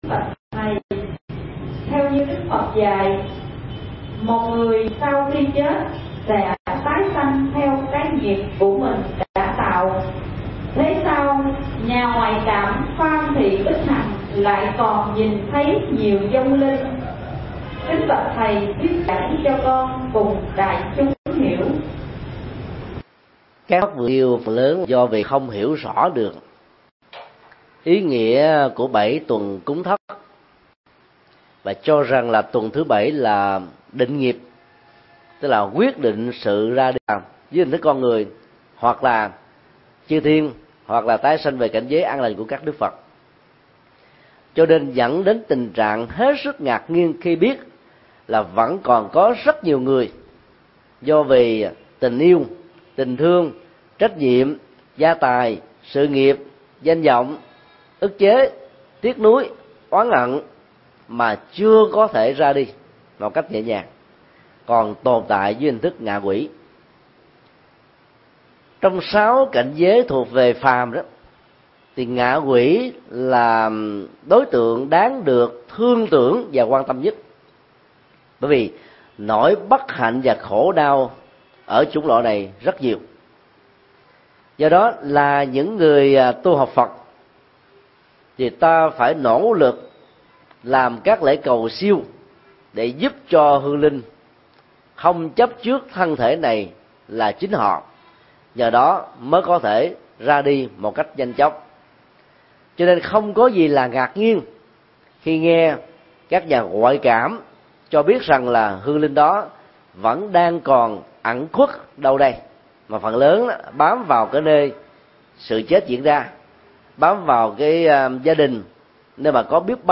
Vấn đáp: Lợi ích của cầu siêu cho hương linh